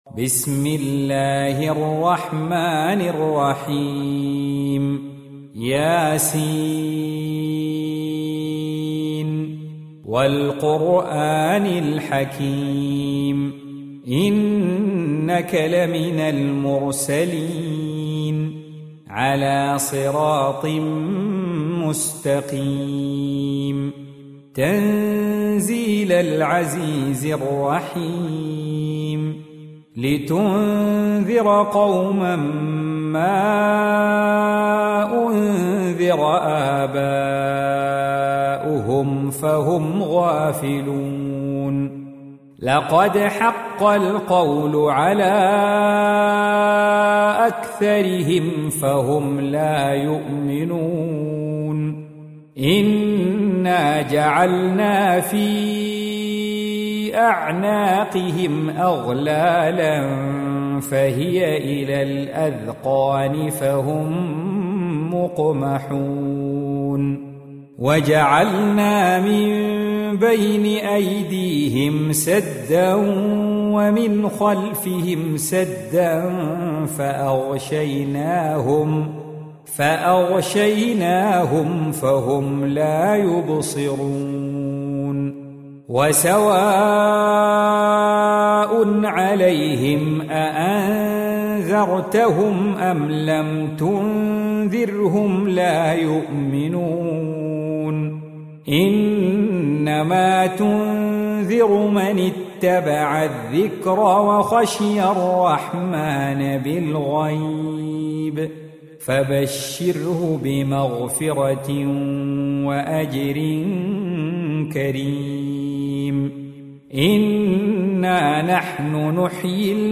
Surah Repeating تكرار السورة Download Surah حمّل السورة Reciting Murattalah Audio for 36. Surah Y�S�n. سورة يس N.B *Surah Includes Al-Basmalah Reciters Sequents تتابع التلاوات Reciters Repeats تكرار التلاوات